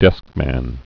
(dĕskmăn, -mən)